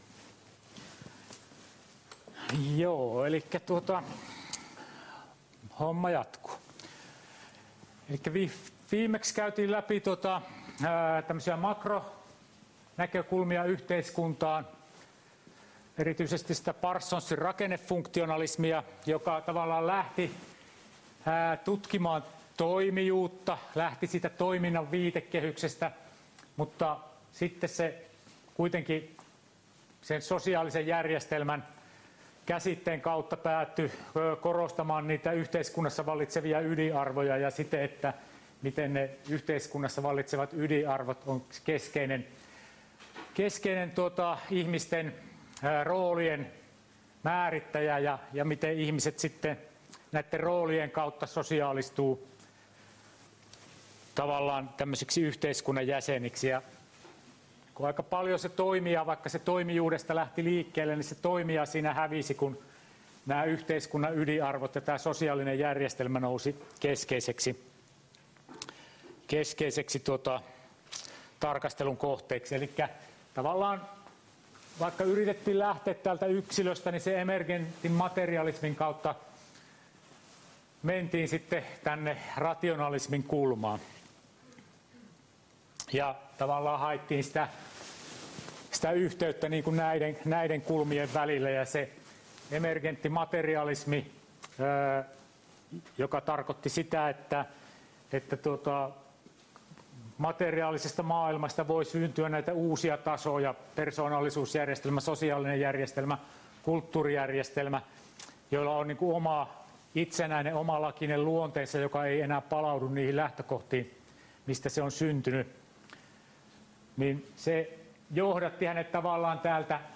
Luento 10.11.2021 — Moniviestin